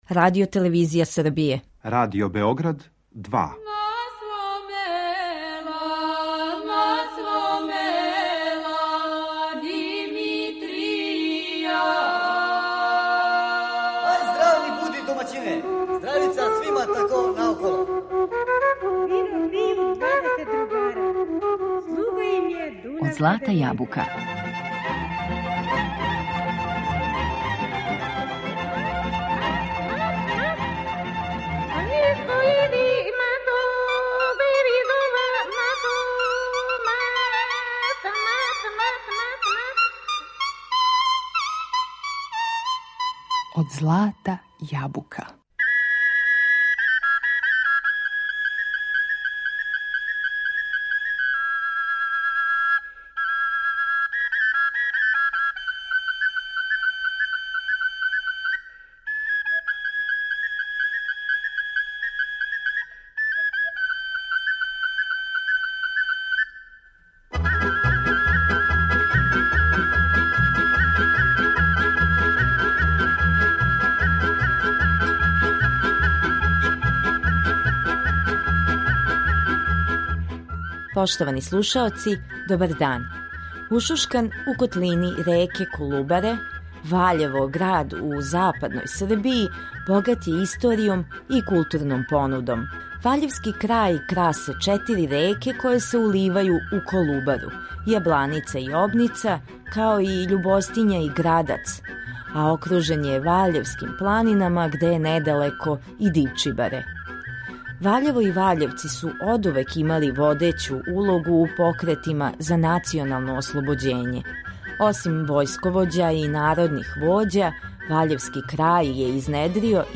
Први сабор свирача на двојницама и окарини у Ваљеву
У данашњем издању емисије Од злата јабука водимо вас у Ваљево, на Први сабор свирача на двојницама и окарини, који је одржан у недељу, 9. октобра.
Такмичари су изводили једну песму, којој су могли претходити рабаџијска свирка и коло, без временског ограничења.
Такмичари су показали изузетан квалитет и посвећеност нашој музичкој традицији, а у данашњој емисији слушате најбоље музичаре који су награђени за извођење на ова два инструмента.